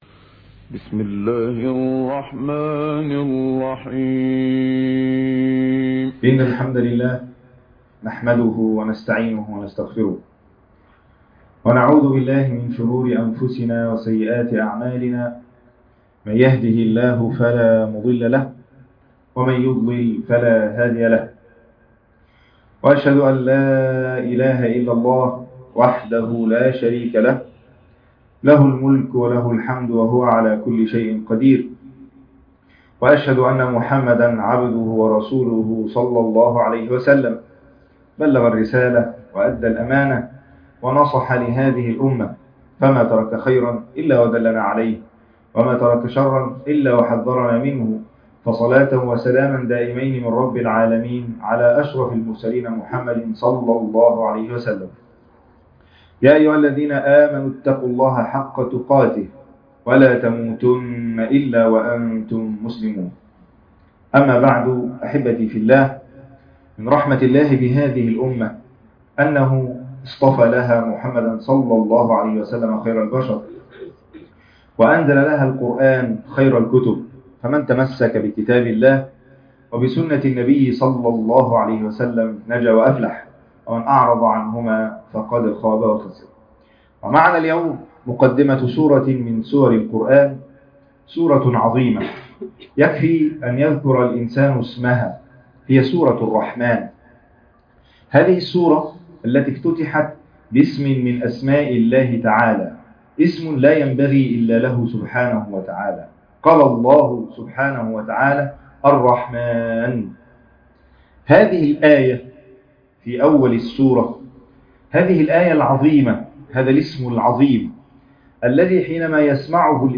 عنوان المادة وقفات مع مقدمة سورة الرحمن خطبة جمعة تاريخ التحميل الخميس 22 يونيو 2023 مـ حجم المادة 12.37 ميجا بايت عدد الزيارات 319 زيارة عدد مرات الحفظ 128 مرة إستماع المادة حفظ المادة اضف تعليقك أرسل لصديق